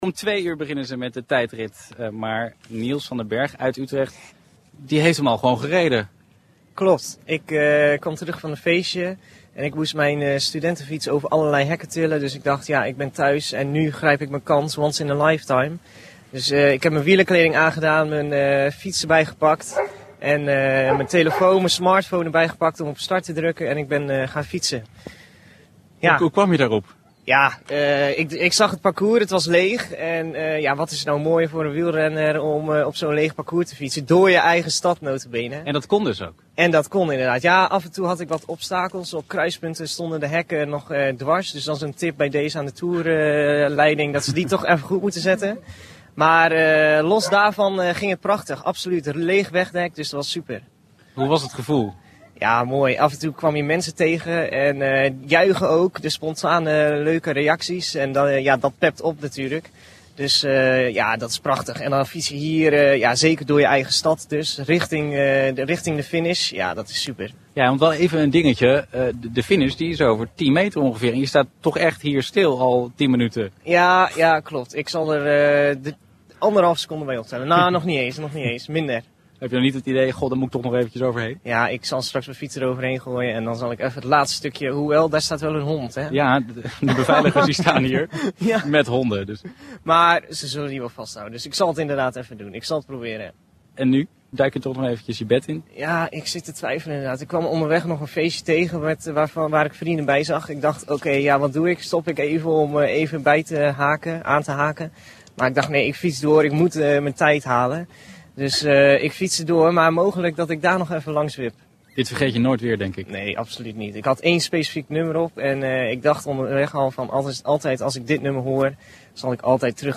Ik maakte de hele nacht live-reportages voor Radio M Utrecht.